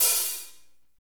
HAT F S L06L.wav